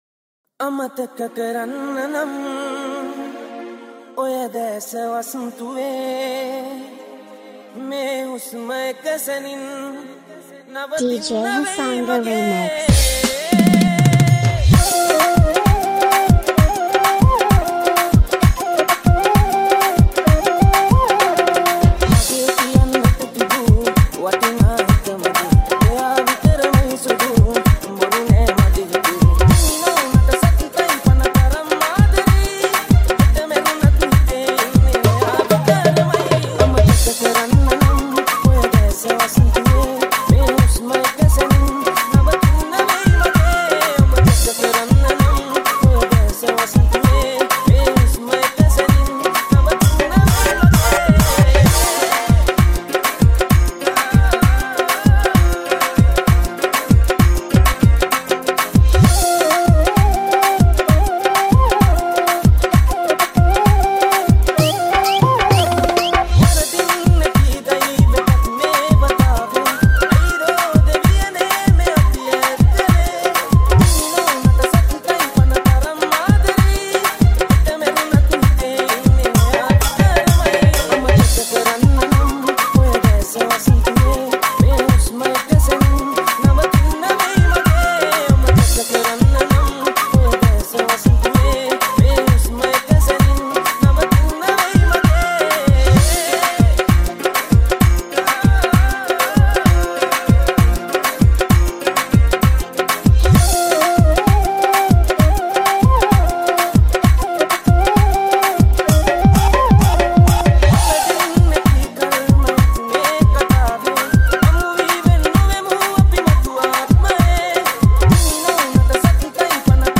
6-8 Kawadi Dance Remix